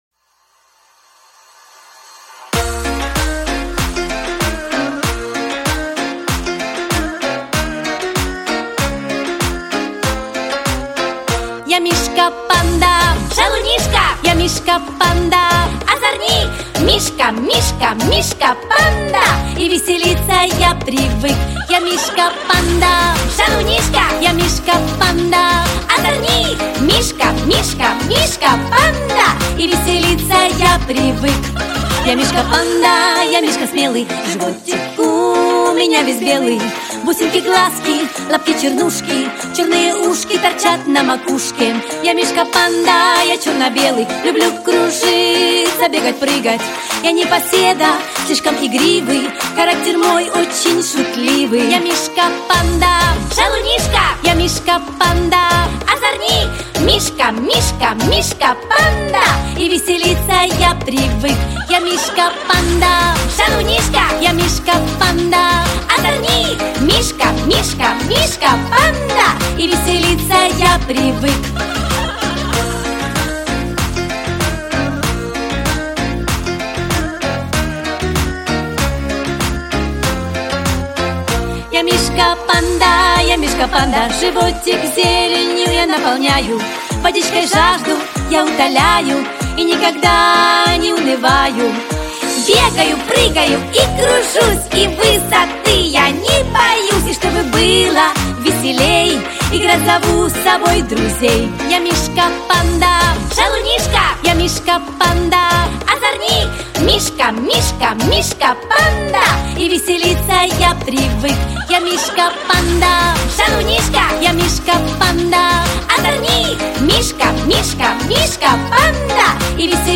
• Категория: Детские песни
малышковые